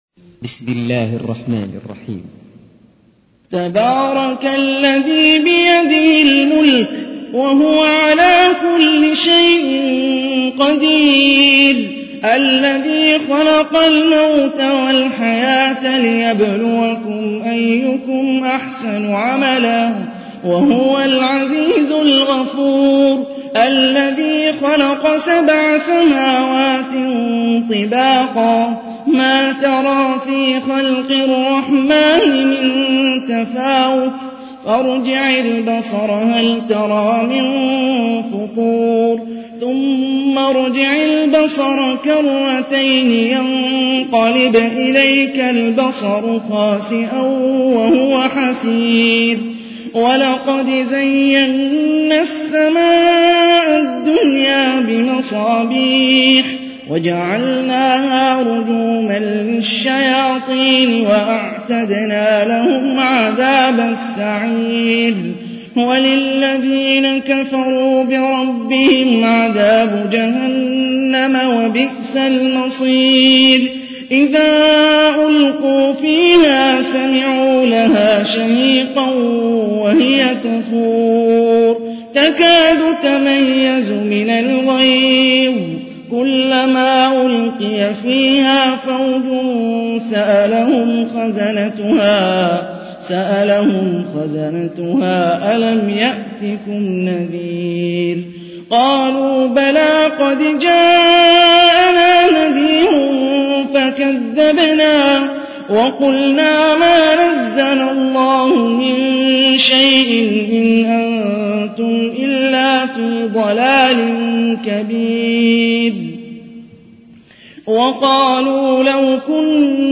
تحميل : 67. سورة الملك / القارئ هاني الرفاعي / القرآن الكريم / موقع يا حسين